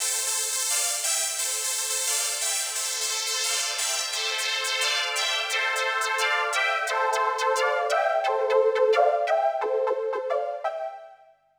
Tech Step 2 Synth 7.WAV